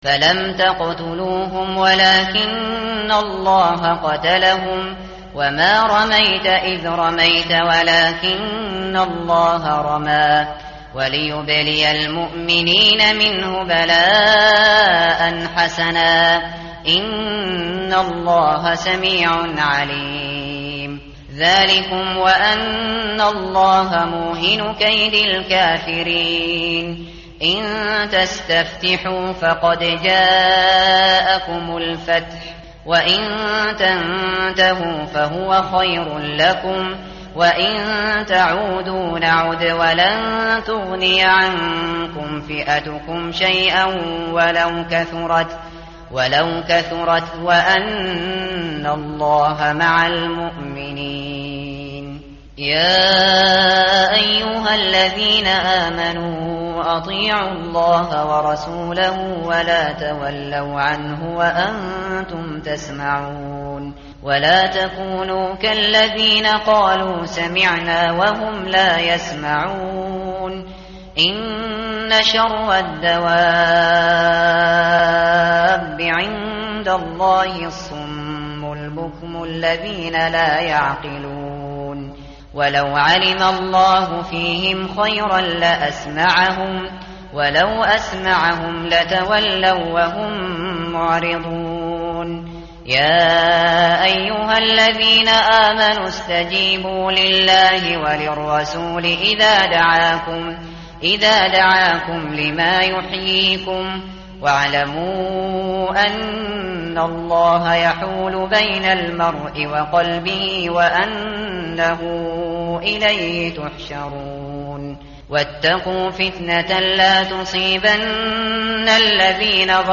متن قرآن همراه باتلاوت قرآن و ترجمه
tartil_shateri_page_179.mp3